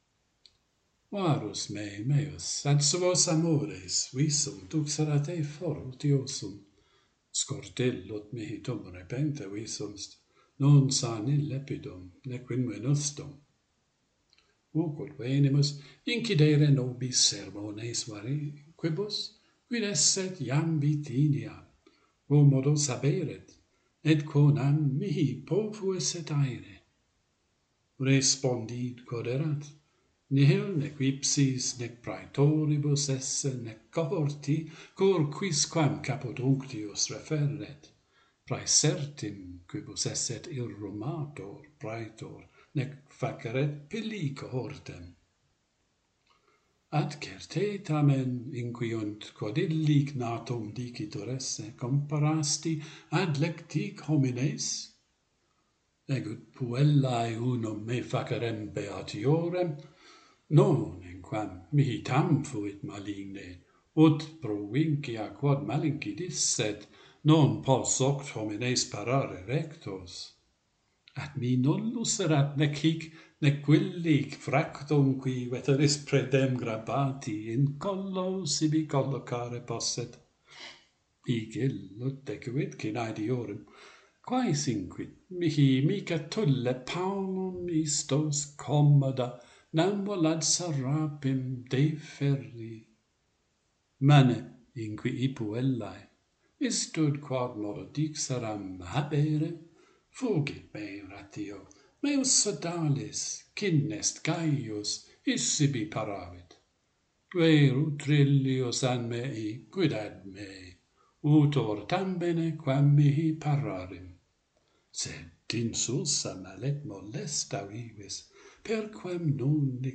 A fashionable conversation - Pantheon Poets | Latin Poetry Recited and Translated